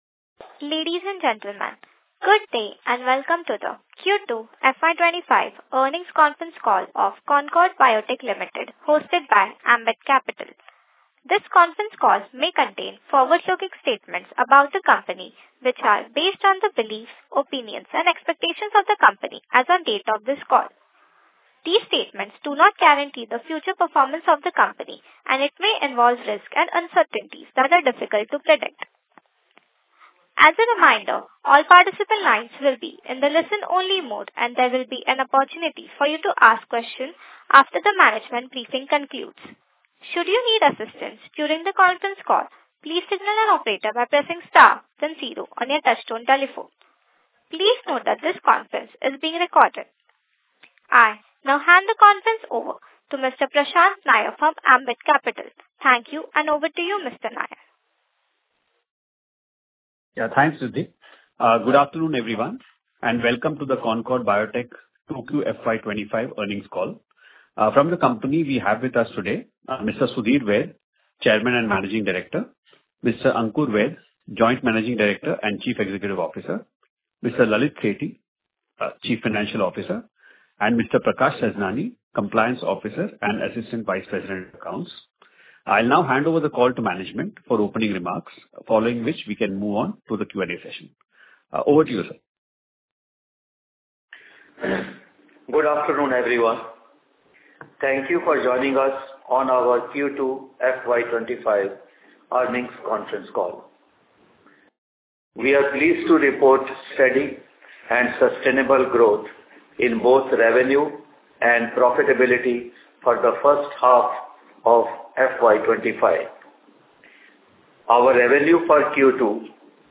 Concord-Biotech-Q2FY25-Earnings-Call-Audio.mp3